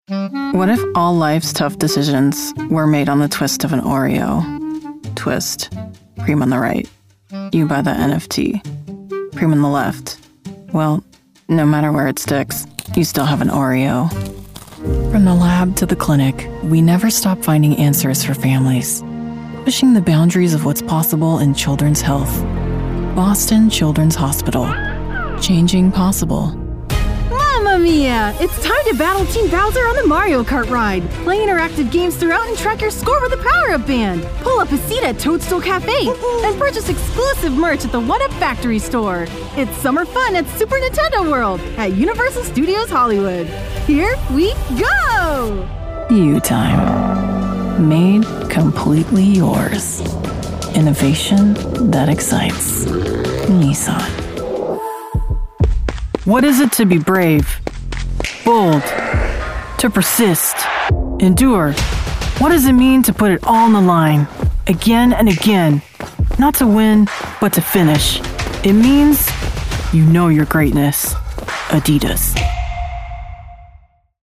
In commercial she is highly dynamic and adaptable from the stoic and grounded, to warm/friendly, with a touch of quirky charm. Her natural voice print is right at home in luxury branding and lighthearted spots, with spot-on comedic timing that brings any copy to life.
COMMERCIAL 💸
broadcast level home studio